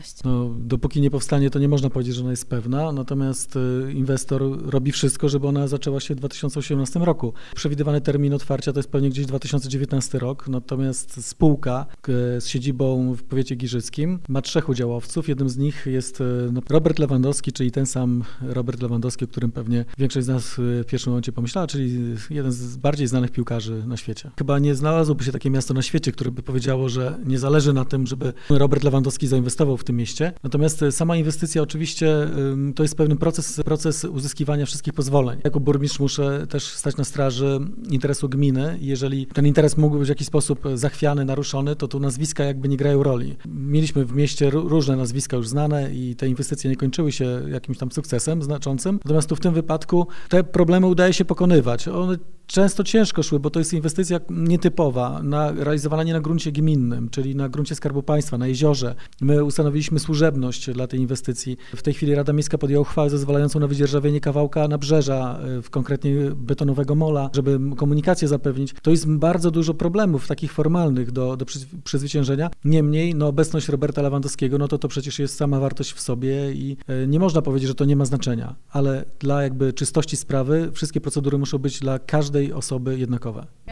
– Bez połączenia z molo inwestycja nie miałaby racji bytu – mówi Wojciech Karol Iwaszkiewicz, burmistrz Giżycka.